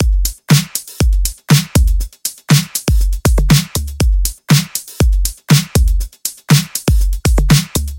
描述：对各种循环进行编程，并将它们分层，然后我通过一个突变效果处理整个循环
标签： 重低音 舞曲 电子乐 丛林
声道立体声